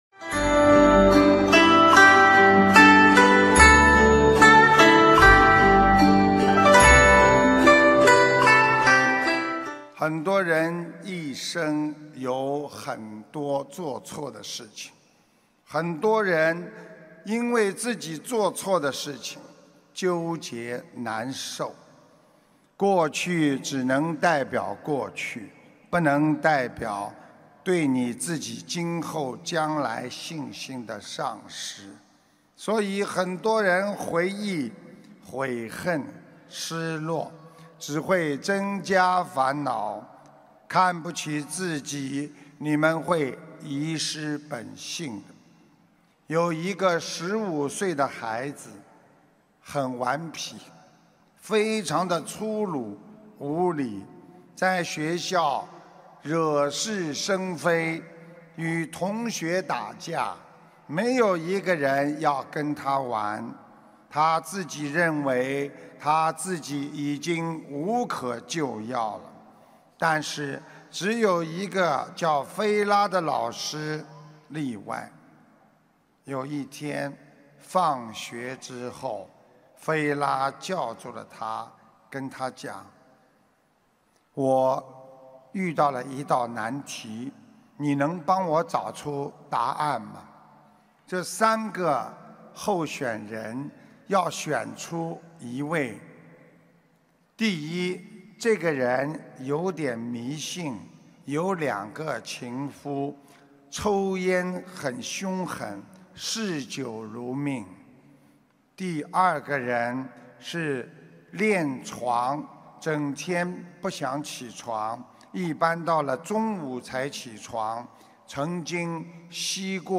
音频：过去的荣辱只能代表过去，你如果改掉过去的毛病，一切将会重新开始。唤起心中佛性 铸造心灵净土（上）2015年6月20日香港开示摘要!